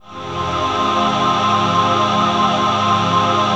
DM PAD4-01.wav